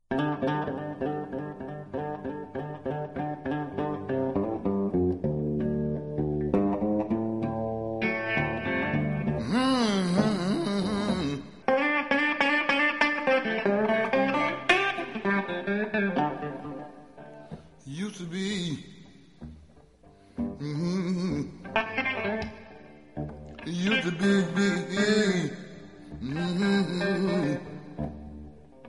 En bonus, le sample en intro et le vocal :